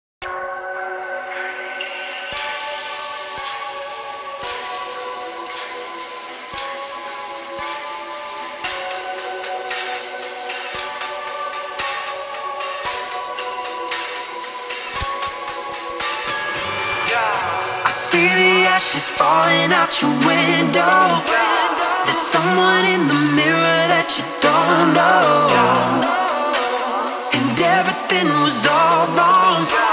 Genre: K-Pop